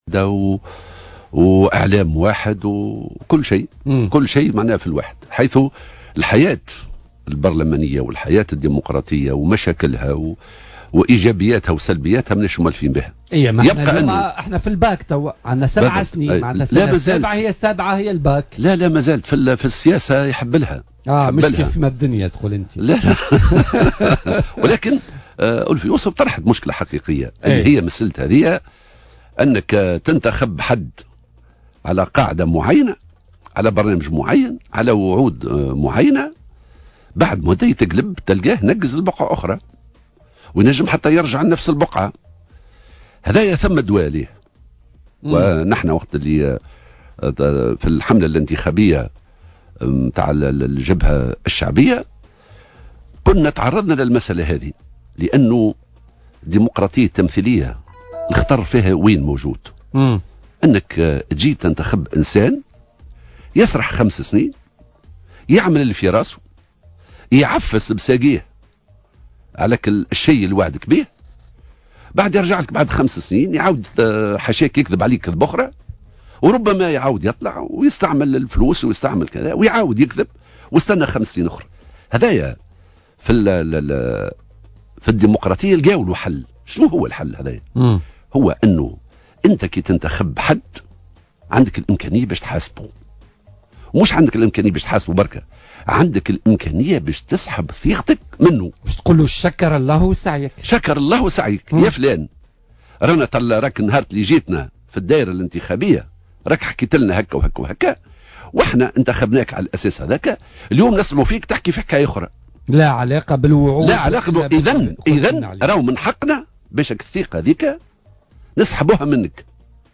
قال الناطق الرسمي بإسم الجبهة الشعبية حمة الهمامي ضيف بولتيكا اليوم الثلاثاء 14 نوفمبر 2017 إن المشكل الحقيقي ليس في شكل النظام السياسي المعمول به في تونس اليوم .